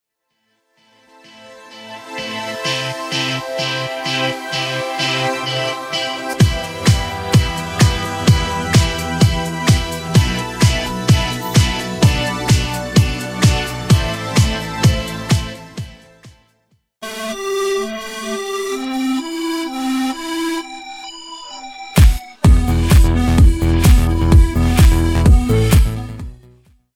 Original Instrumental Extended
Original Instrumental Radio
Instrumental Remix